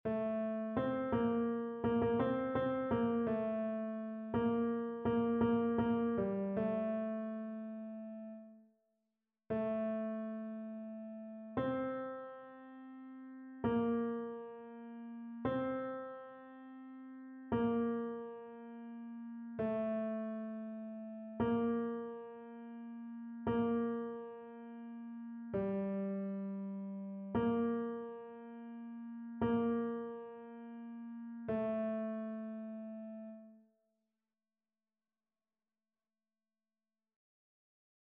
Ténor
annee-b-temps-pascal-5e-dimanche-psaume-21-tenor.mp3